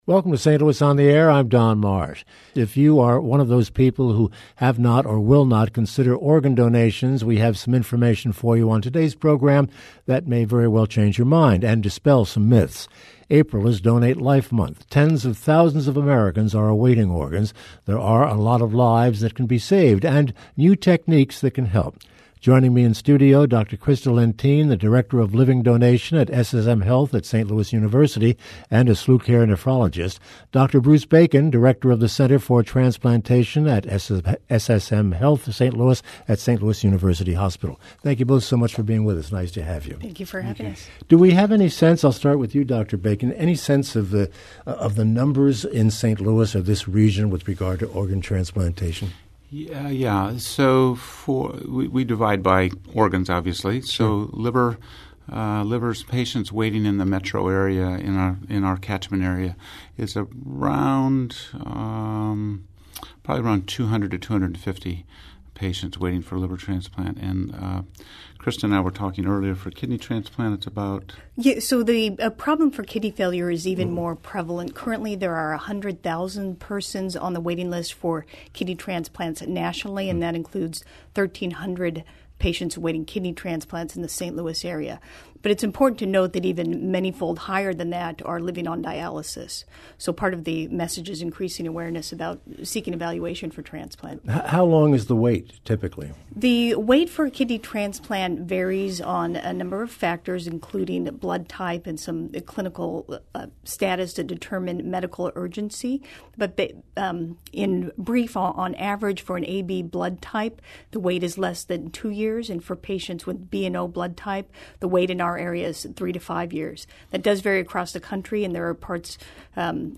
April is organ donation month and two guests joined St. Louis on the Air to discuss new advances in the field of organ donation research.